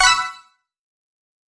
背包-合成元素音效.mp3